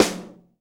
snare 2.wav